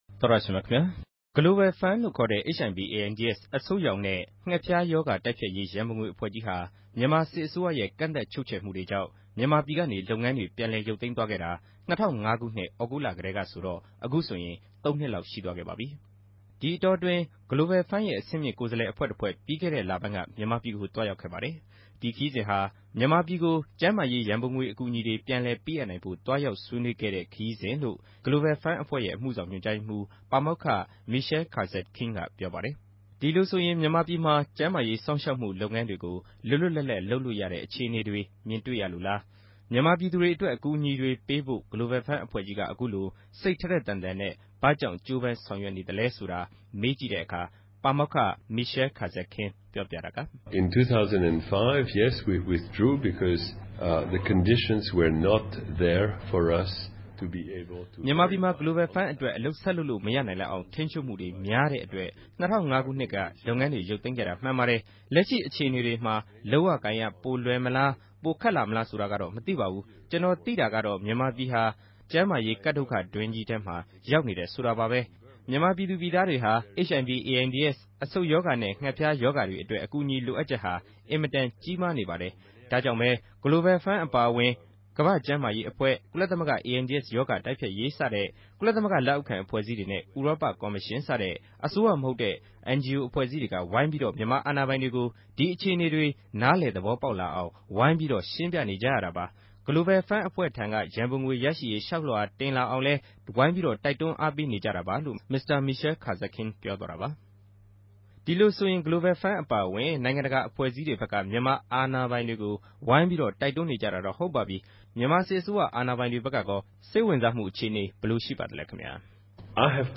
တြေႚဆုံမေးူမန်းခဵက်။